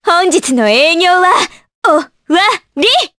Juno-Vox_Skill5_jp.wav